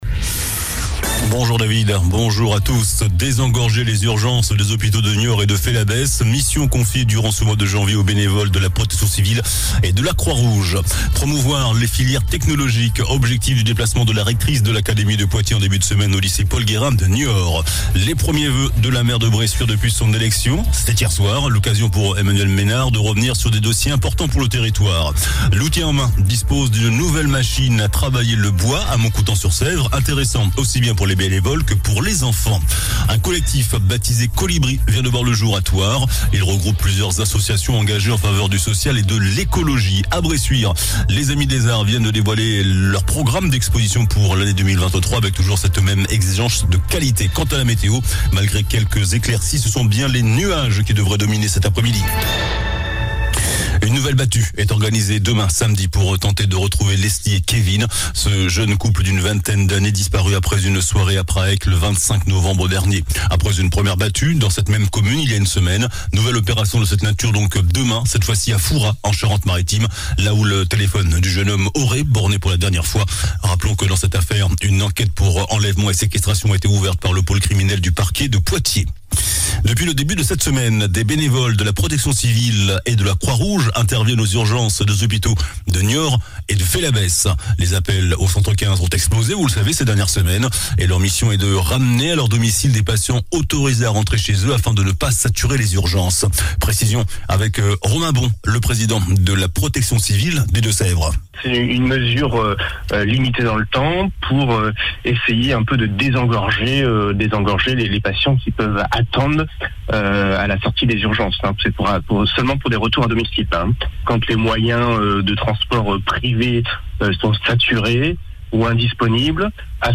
JOURNAL DU VENDREDI 13 JANVIER ( MIDI )